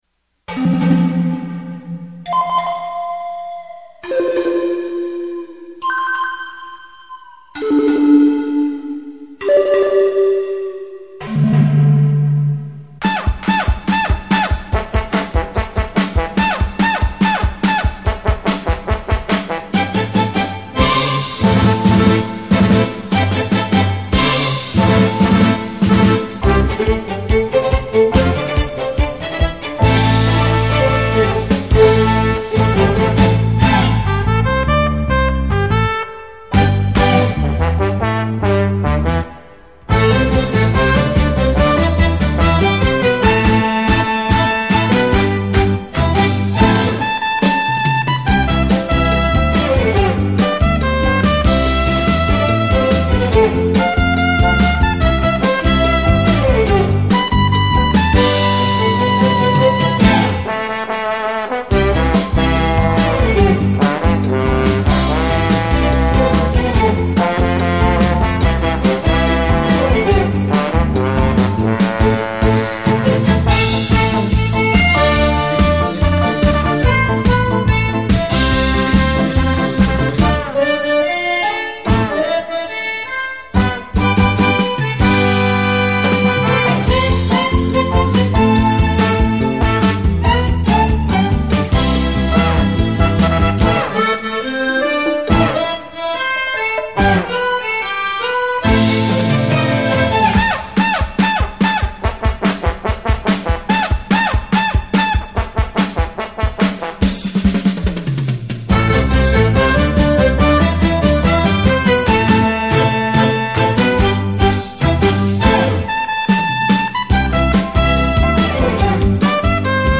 专辑格式：DTS-CD-5.1声道
全音域演奏  暢銷日本歌曲
閒適優雅的音符，完美傳真的音質，呈獻在您的耳際
優美動聽的舞蹈旋律讓人沉醉其中...
靈魂舞